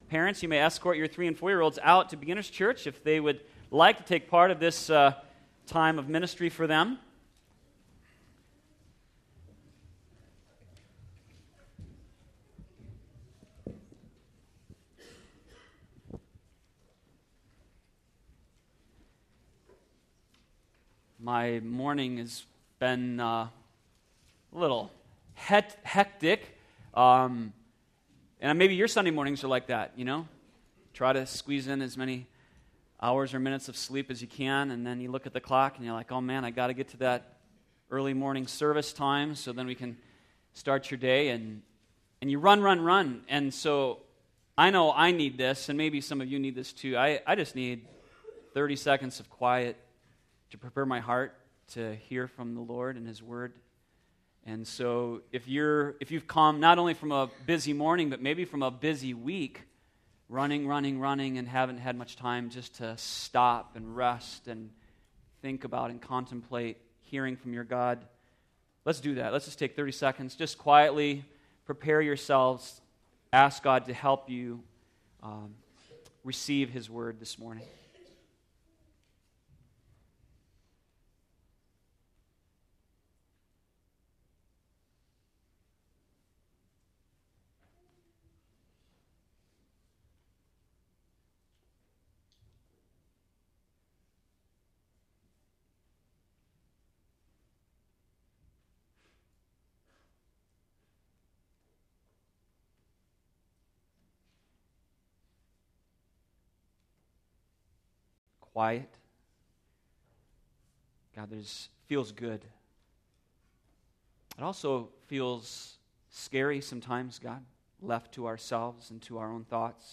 sermon2313.mp3